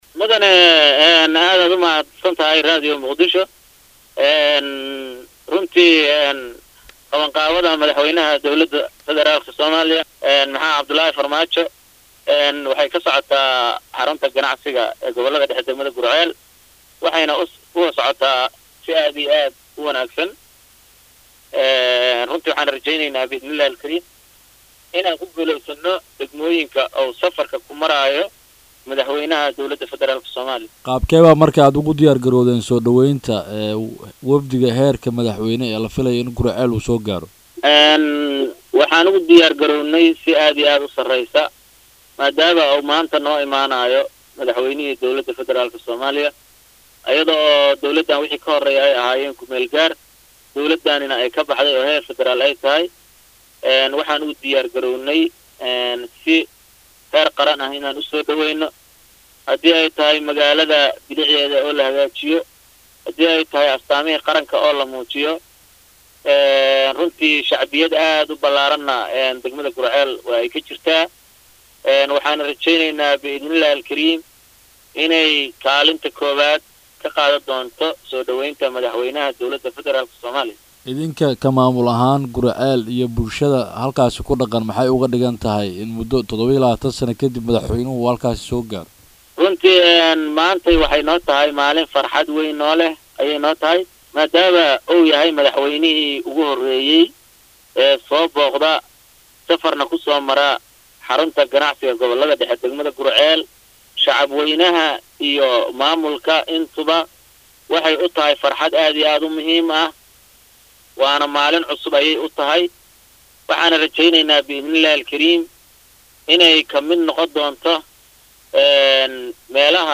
Wareysiyada
Gudoomiyaha Degmada Guriceel Cabdulaahi Maxamad barre Beentaay oo la hadlay Radio Mudio Muqdisho Codka Jamhuuriyadda Soomaaliya ayaa sheegay in magaalada ay ka socoto qaban qaabadii ugu dambeeysay ee lagu soo dhaweynaayo Madaxweyne Farmaajo iyo Mas,uuliyiinta kale ee la socota.